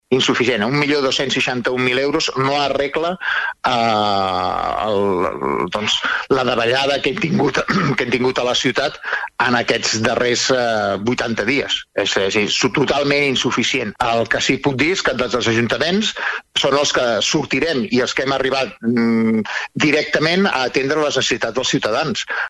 Així ho ha explicat Carles Motas, alcalde de Sant Feliu a Ràdio Capital de l’Empordà.